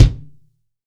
TUBE KICKT-S.WAV